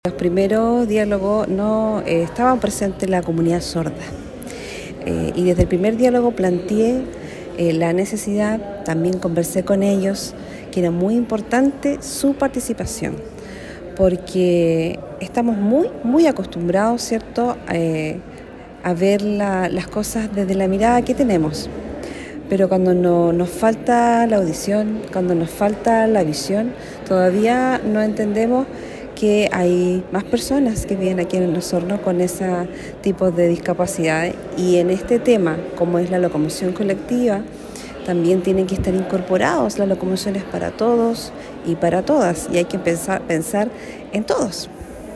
Por último, la Concejala Cecilia Canales, destacó que en la última convocatoria se haya contado con un intérprete de señas, ya que de está forma se puede crear un sistema de transporte donde la opinión de todos y todas sea considerada.